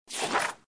GUI_stickerbook_turn.ogg